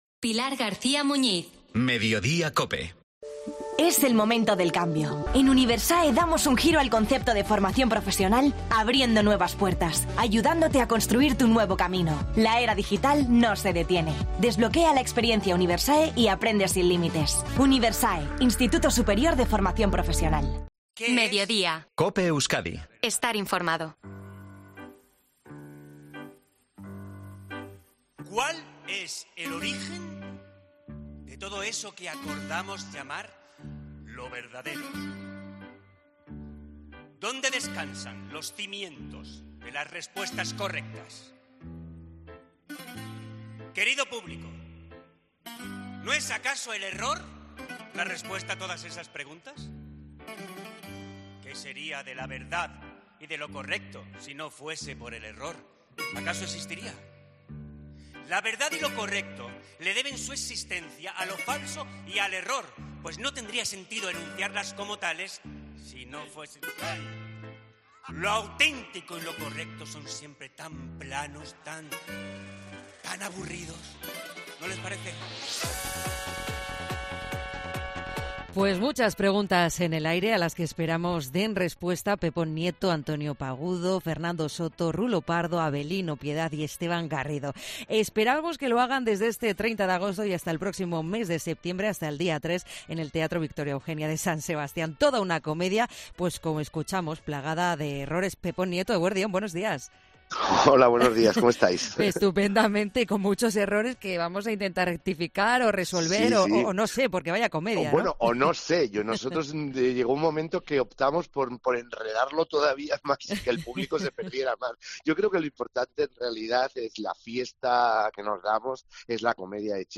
Entrevista a Pepón Nieto, en COPE Euskadi